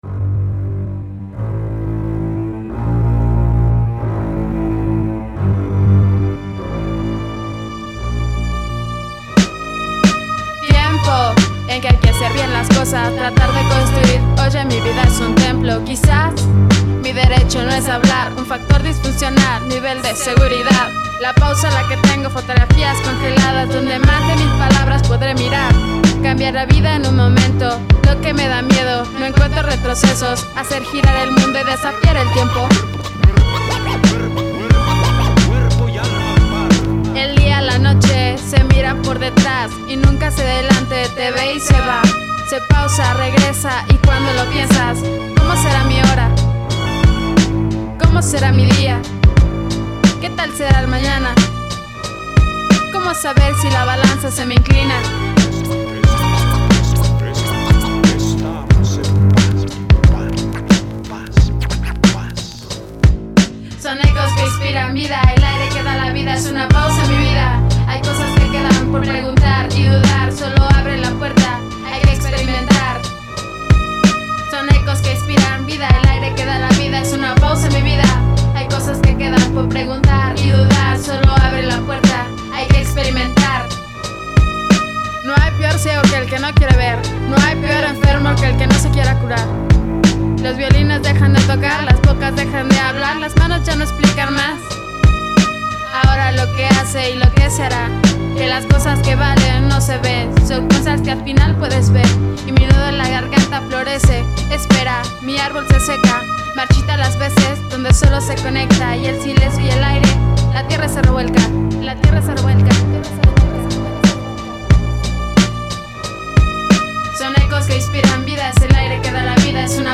Sólo para fans del rap, hip hop y rimas urbanas mexicanas.